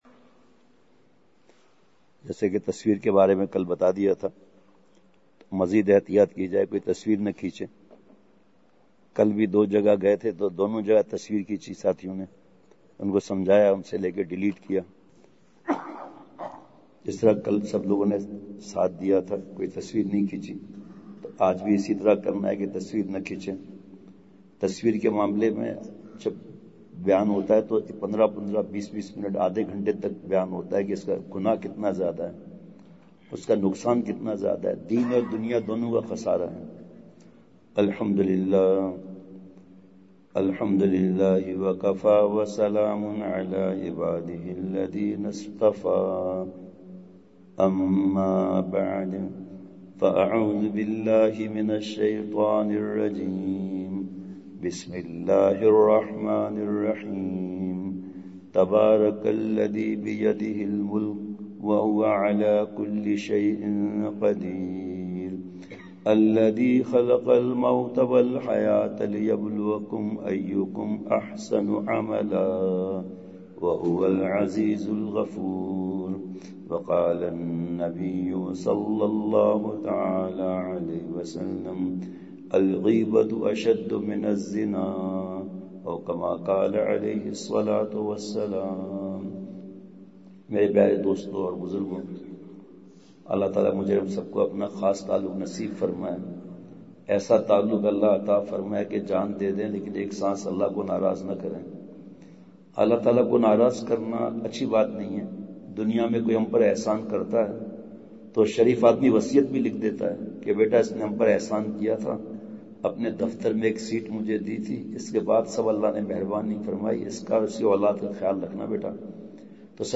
*بمقام:۔مسجد امیر حمزہ ملٹی گارڈن اسلام آباد*
*بعد فجربیان*